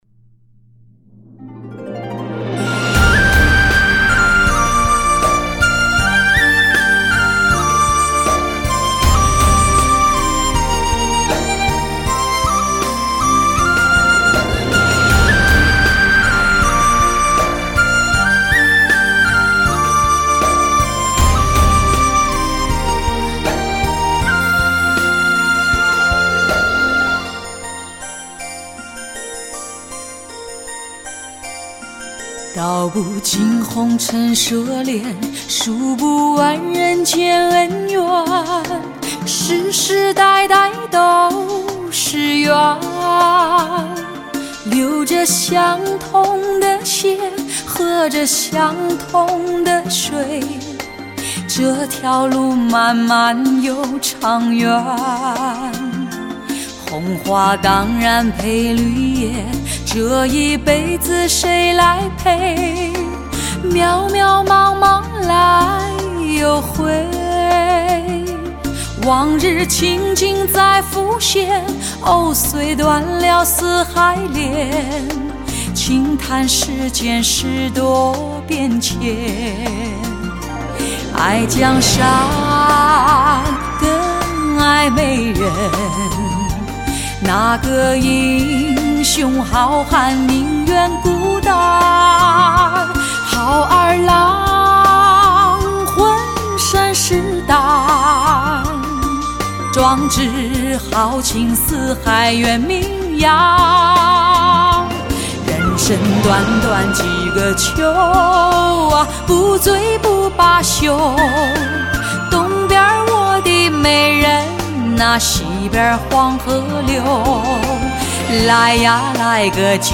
母带级音质 发烧新体验
全面恢复黑胶唱片的空气感和密度感
低音强劲有力，中音清晰丰满，高音柔和圆润，精确的乐器定位，清晰的人声，层次分明，声场辽阔。